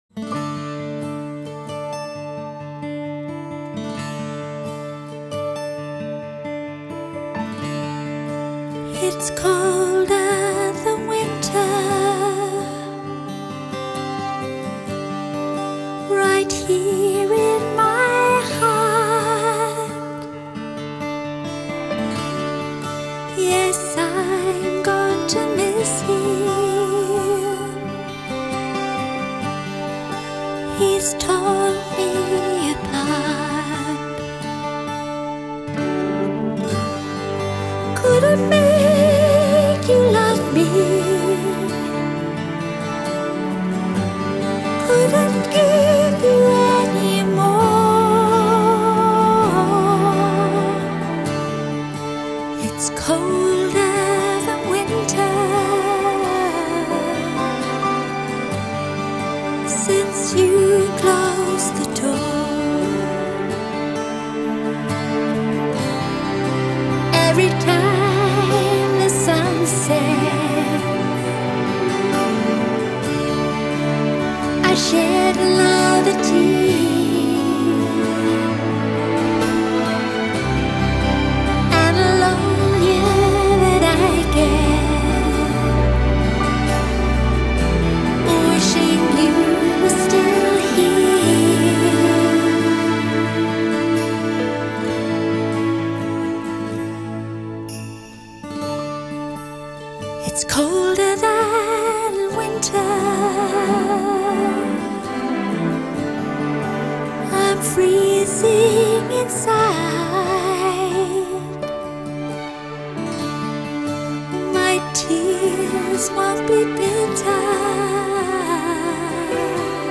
Genre: Crossover
Backing Vocals